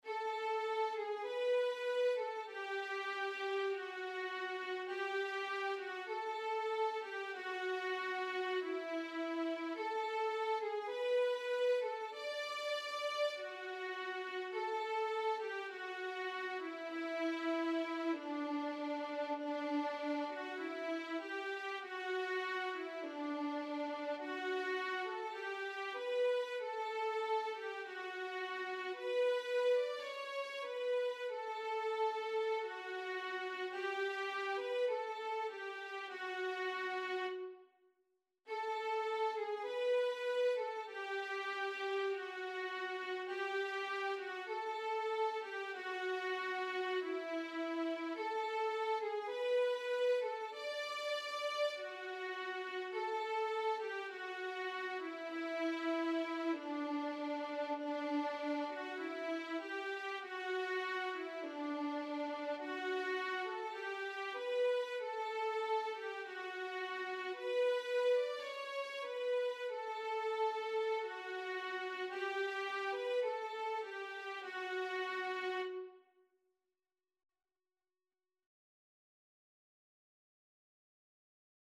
Title: Da bendita cruz Composer: José Sebastião Netto Lyricist: Number of voices: 1v Voicing: Unison Genre: Sacred, Sacred song
Language: Portuguese Instruments: Organ